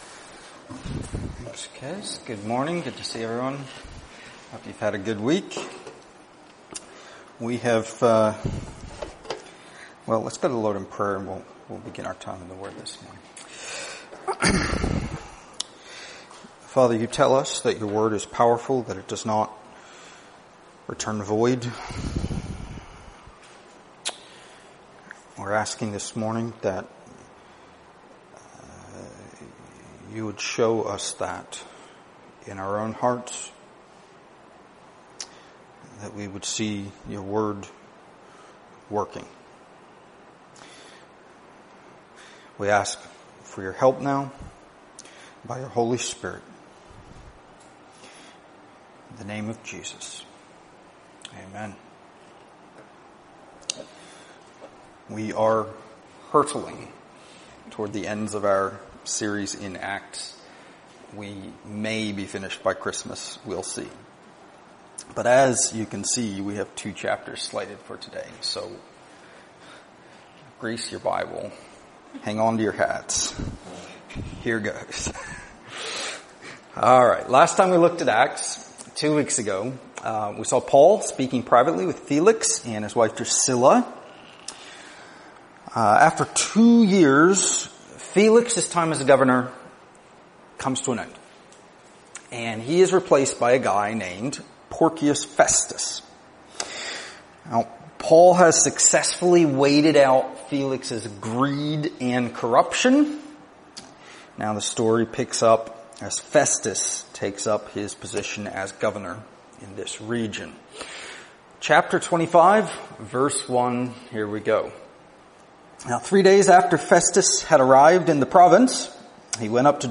Download mp3 Previous Sermon of This Series Next Sermon of This Series